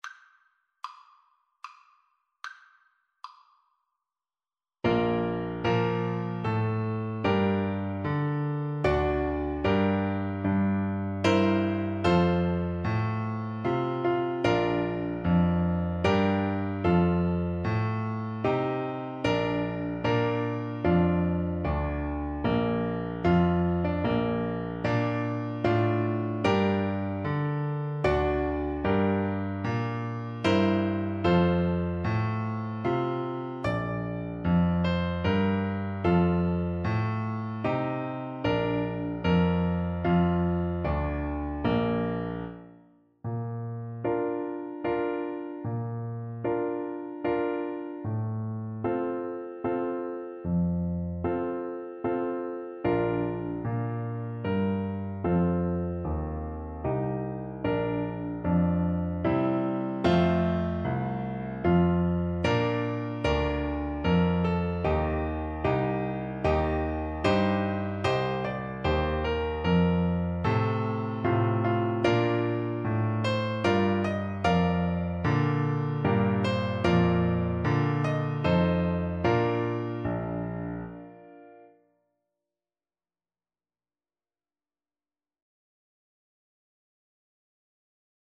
Tuba
Traditional Music of unknown author.
Bb major (Sounding Pitch) (View more Bb major Music for Tuba )
3/4 (View more 3/4 Music)
Maestoso